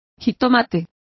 Complete with pronunciation of the translation of tomato.